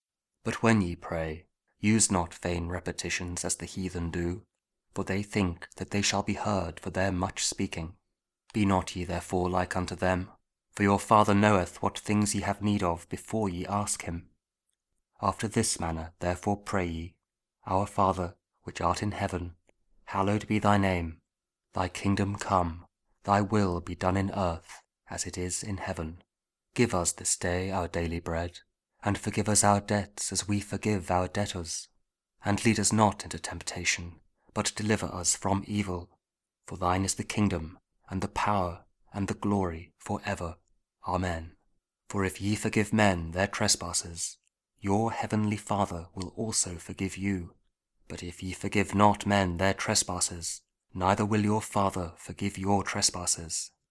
YouTube: Our Father | The Lord’s Prayer | Gospel Of Jesus | Lent | King James Audio Bible
Our-Father-Lent-King-James-Audio-Bible-Lords-Prayer-KJV.mp3